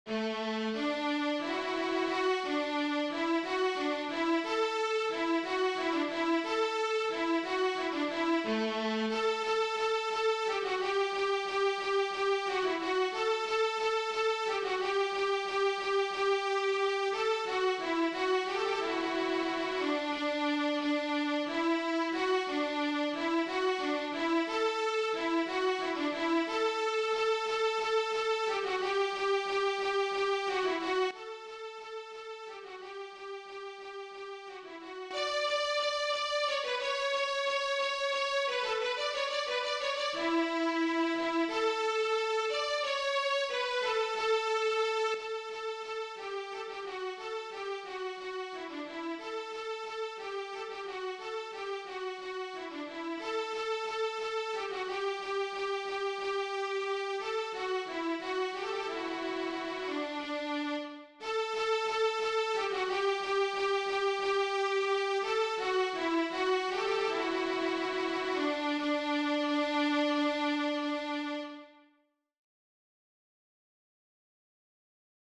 DIGITAL SHEET MUSIC - VIOLIN SOLO